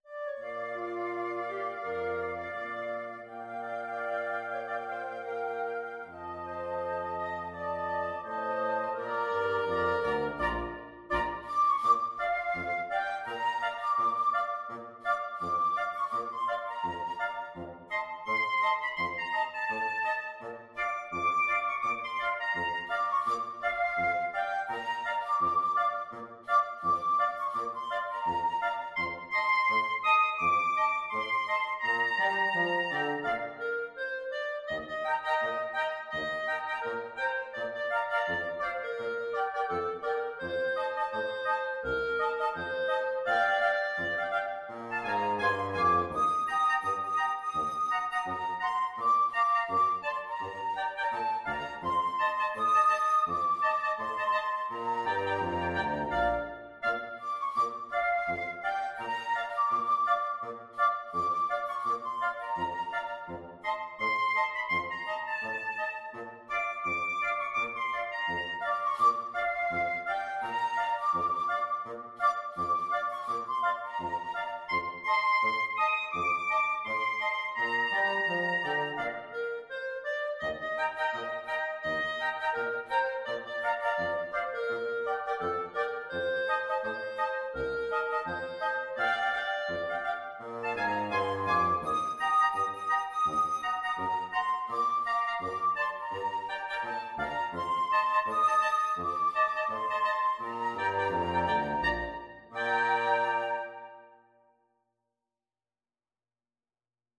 Free Sheet music for Wind Quartet
FluteOboeClarinetBassoon
Bb major (Sounding Pitch) (View more Bb major Music for Wind Quartet )
2/2 (View more 2/2 Music)
Calmly =c.84
Wind Quartet  (View more Easy Wind Quartet Music)
Traditional (View more Traditional Wind Quartet Music)